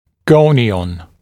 [‘gəunɪˌɔn][‘гоуниˌон]гонион (Go) (цефалометрическая точка)